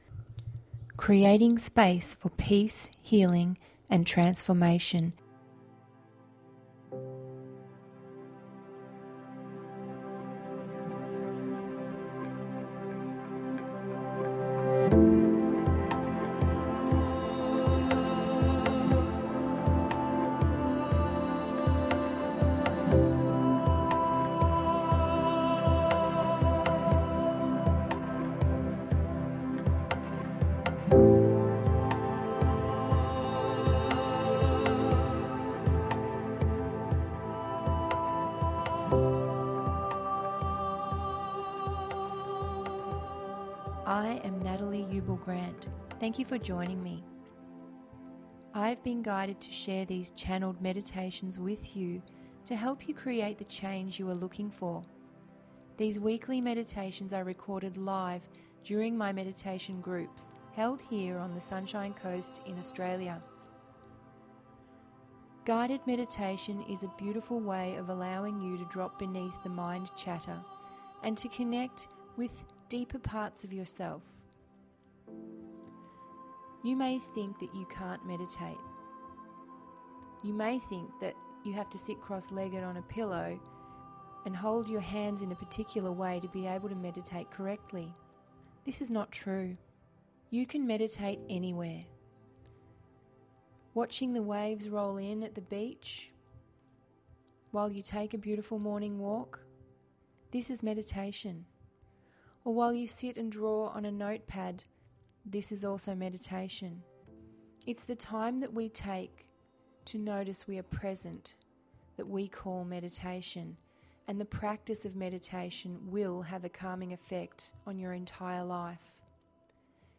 Dive into this meditation where we journey into an underwater wonderland. See symbology and patterns, as you dive deep within your mind. This simple to follow guided meditation podcast is a peaceful get away from your busy week.